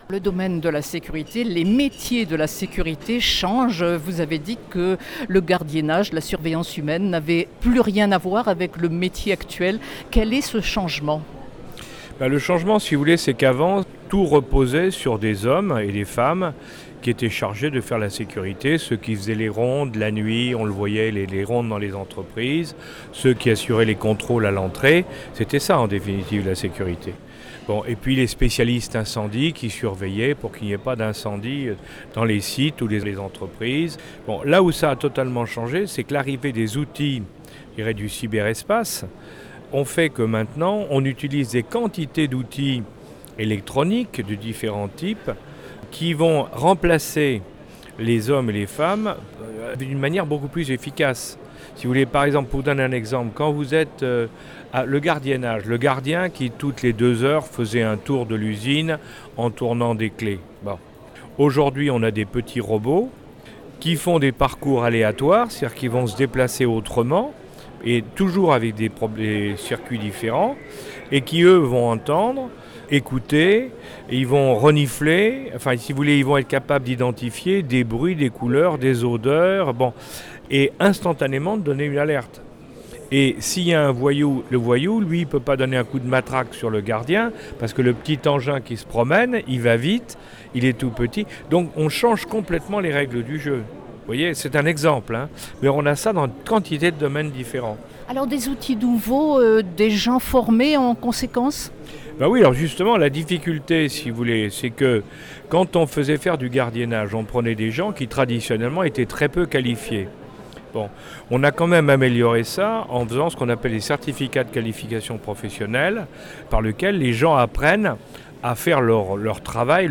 AccesSecurity, le Salon Méditerranéen de la sécurité globale, dont la cybersécurité, a ouvert ses portes, ce mercredi 29 mars pour 3 journées au Parc Chanot à Marseille.